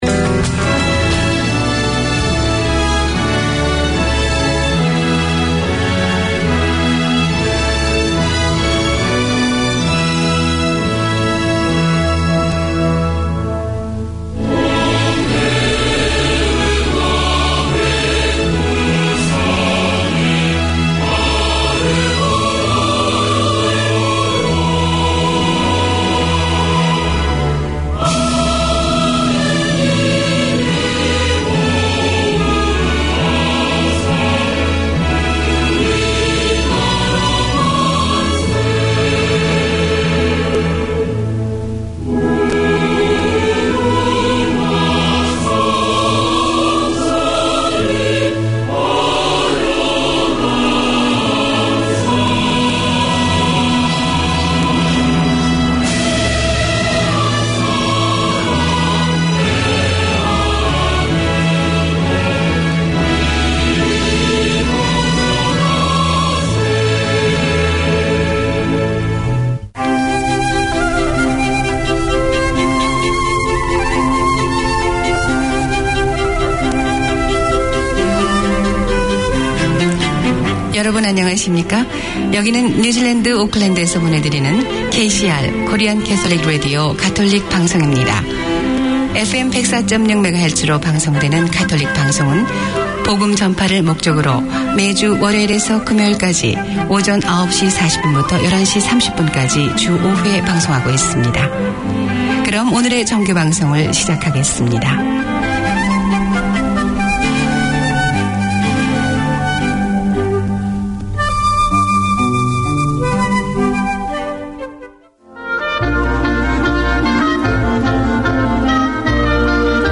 Community Access Radio in your language - available for download five minutes after broadcast.
This half hour series features interviews and discussions on matters of interest to women in general and migrant women in particular. Women working in the community talk to women with shared experiences, to people who can help, to female achievers.